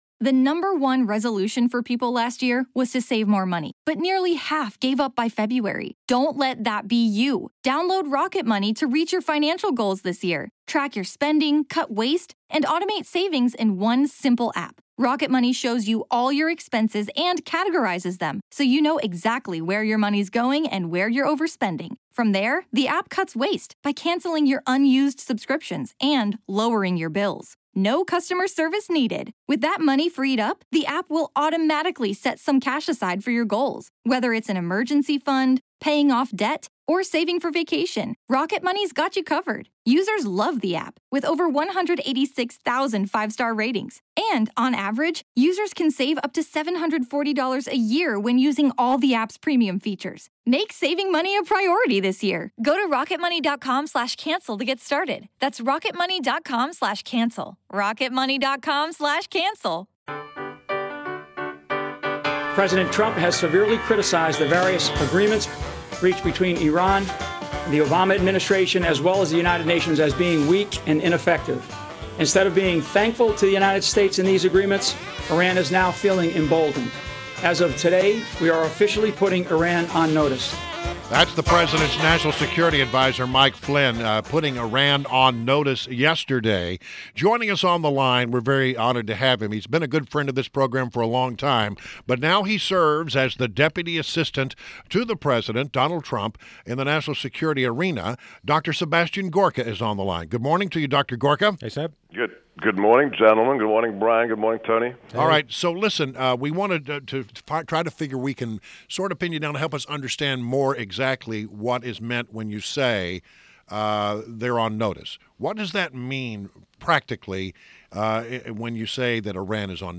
INTERVIEW — DR. SEBASTIAN GORKA — Deputy Assistant to President Donald Trump